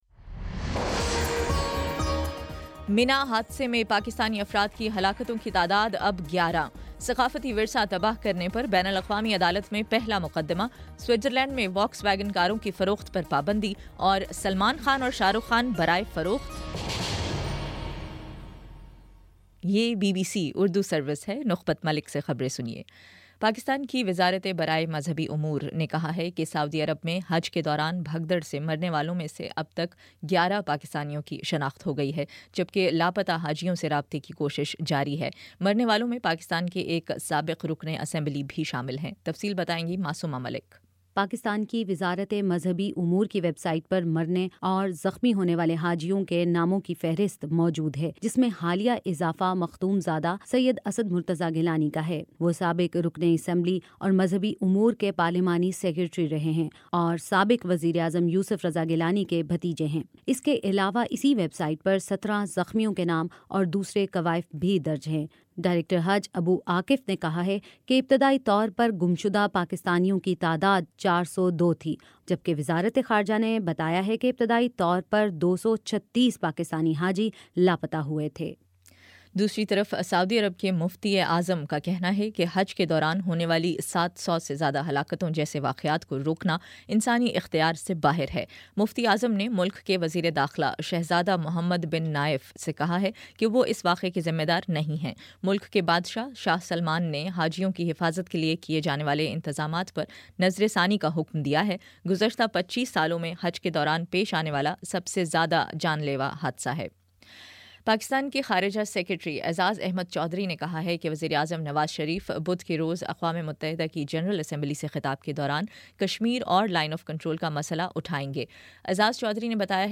ستمبر26 : شام پانچ بجے کا نیوز بُلیٹن